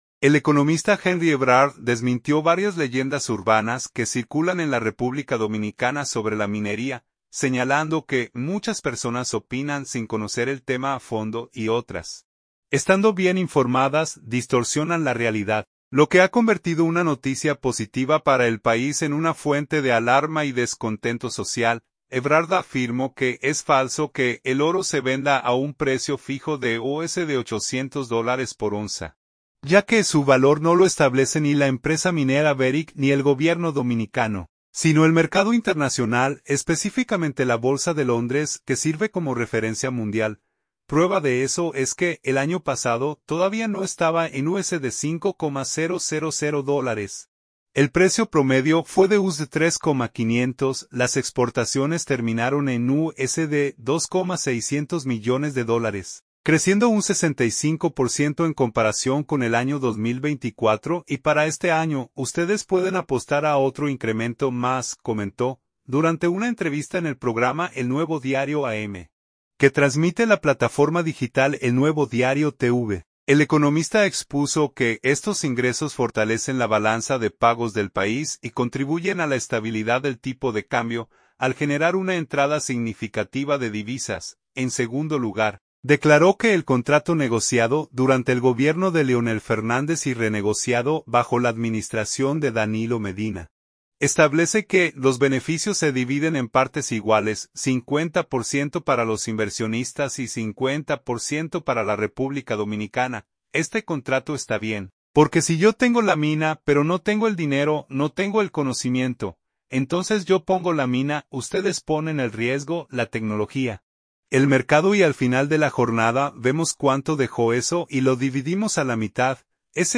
Durante una entrevista en el programa “El Nuevo Diario AM”, que transmite la plataforma digital El Nuevo Diario TV, el economista expuso que estos ingresos fortalecen la balanza de pagos del país y contribuyen a la estabilidad del tipo de cambio, al generar una entrada significativa de divisas.